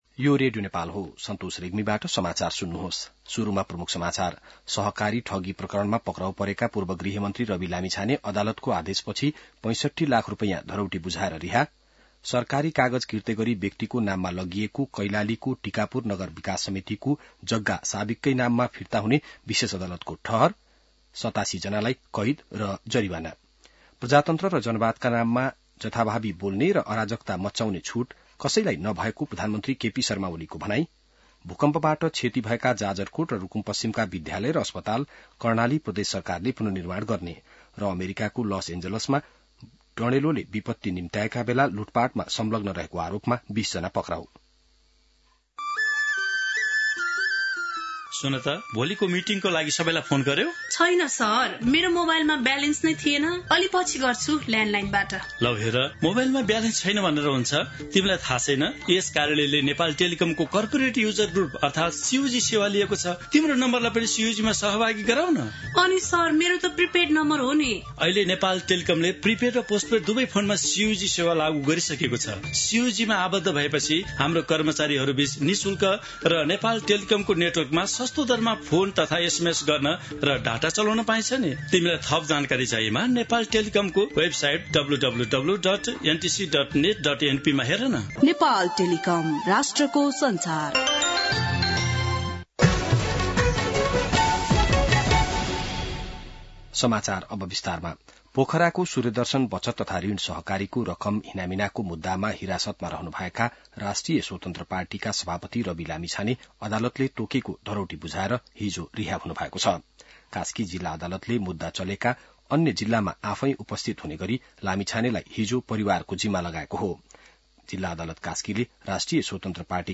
बिहान ७ बजेको नेपाली समाचार : २७ पुष , २०८१